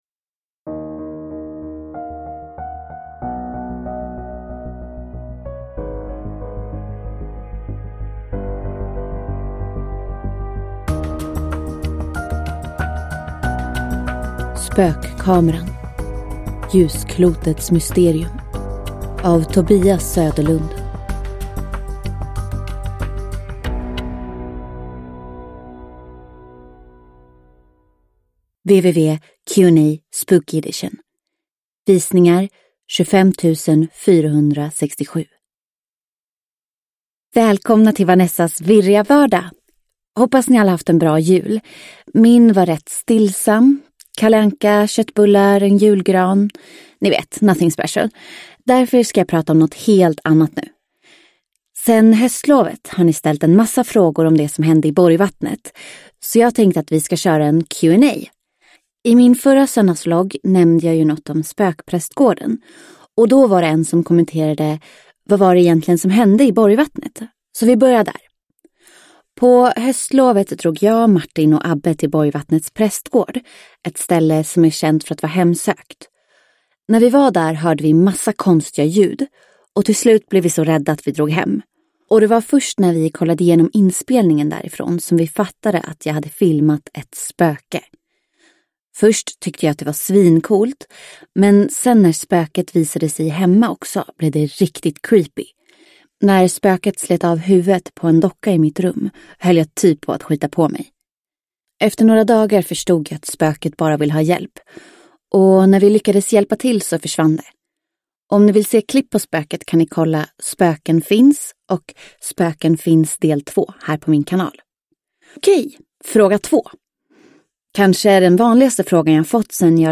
Ljusklotets mysterium – Ljudbok – Laddas ner